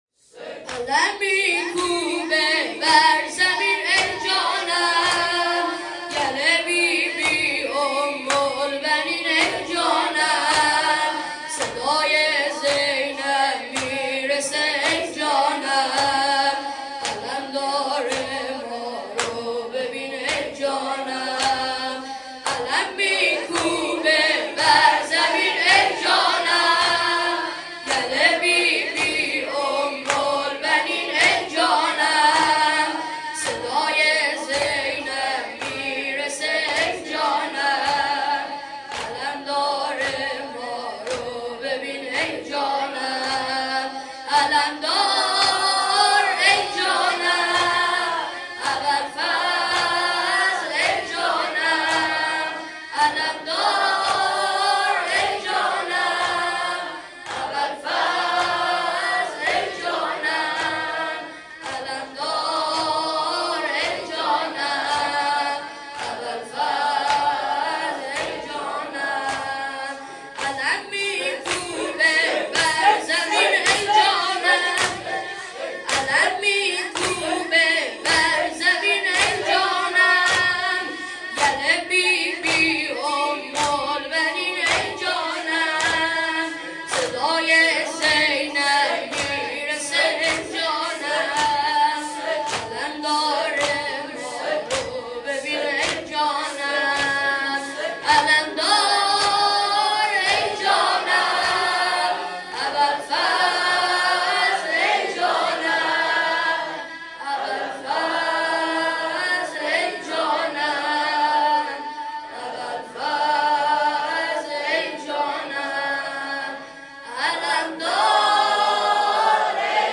شب ۲۴ محرم ۹۷